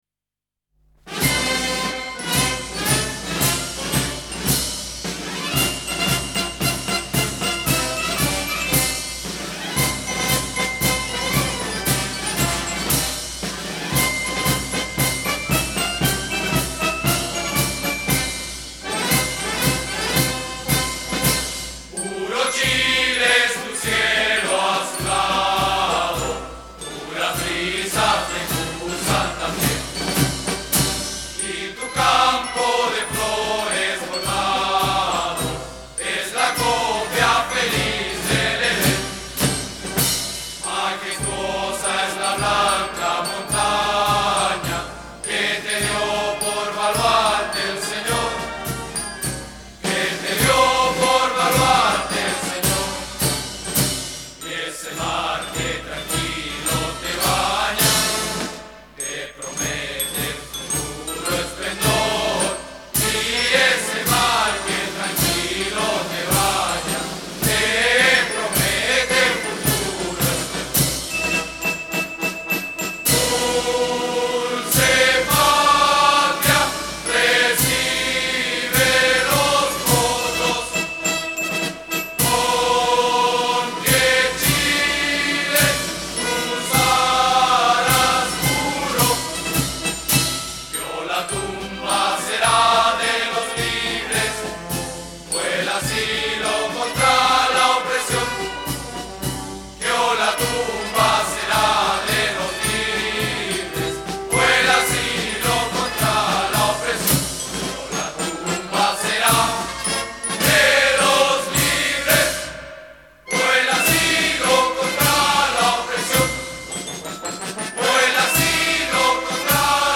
Orquesta
Música vocal